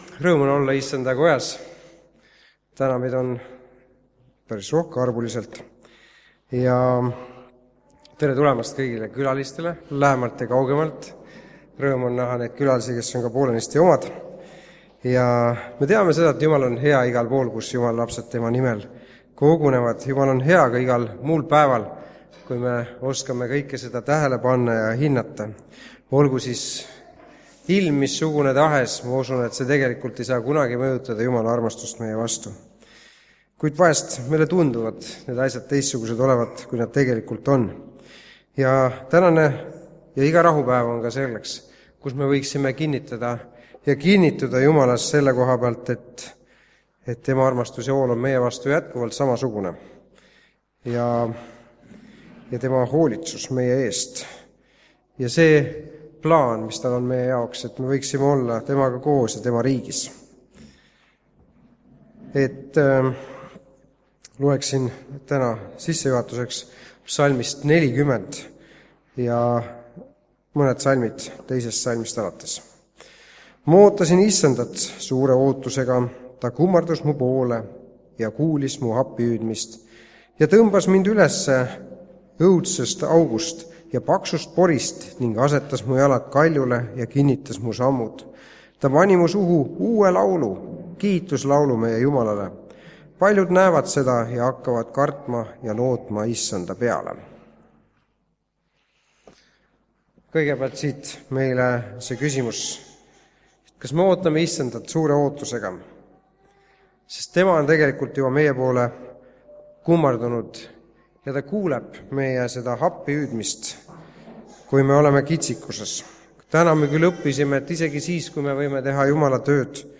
Tunnistuste koosolek (Haapsalus)